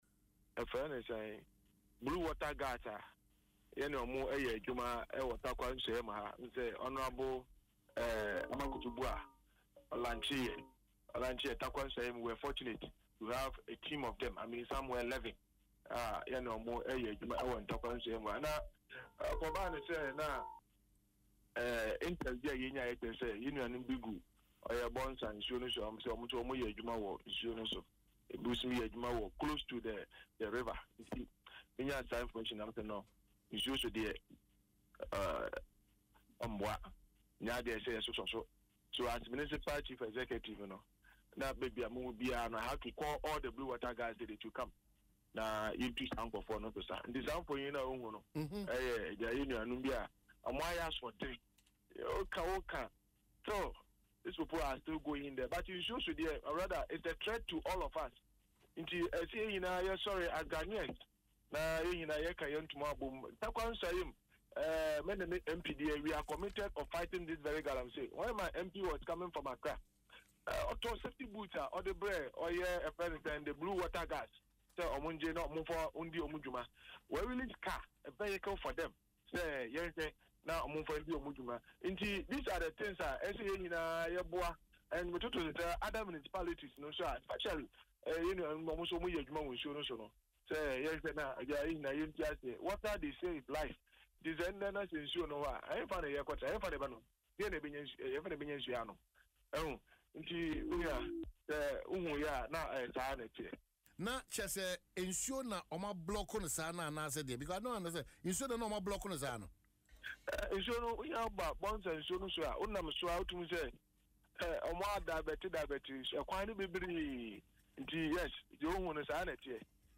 Speaking in an interview on Adom FM’s Dwaso Nsem, Mr. Cobbinah revealed that intelligence reports indicated illegal miners were operating along the Bonsa River, prompting the assembly to deploy its 11-member “Blue Waterguards” taskforce to the site.